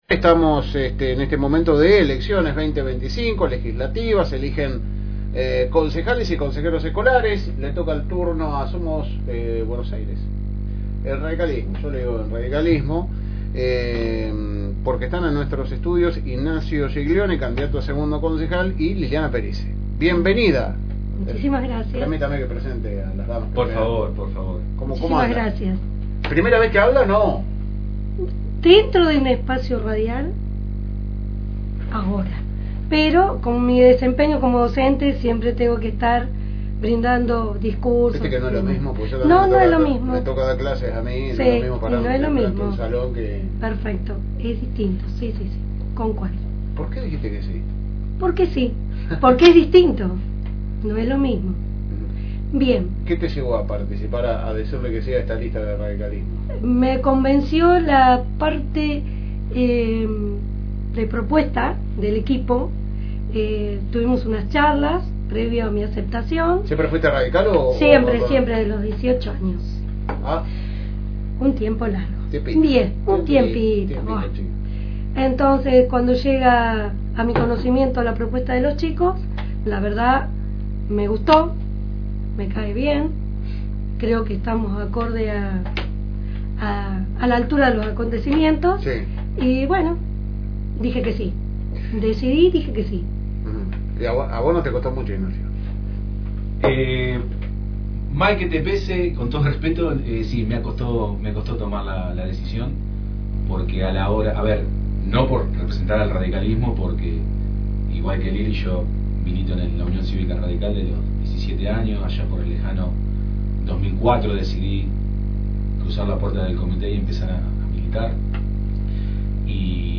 Pasaron por los estudios de FM Reencuentro